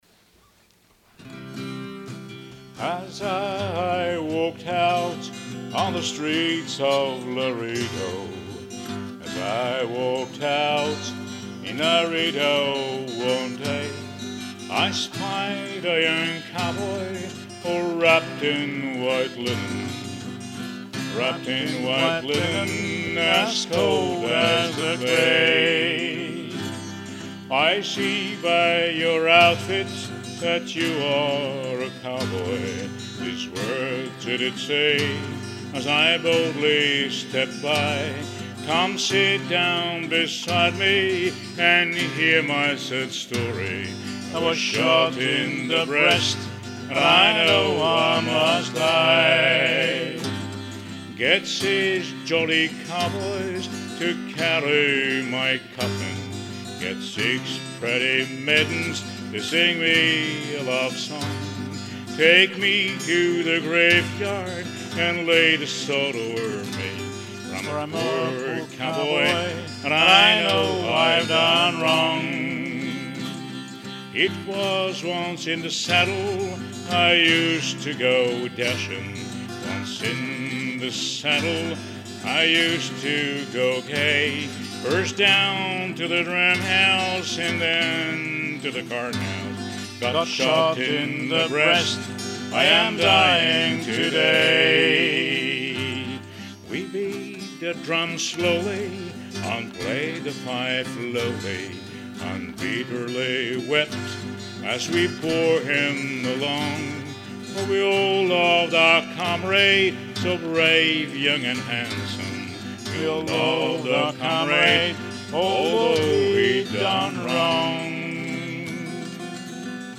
Chanson traditionnelle du Texas connue sous deux titres : "The Cowboy's Lament" ou, plus fréquemment "The Streets of Laredo".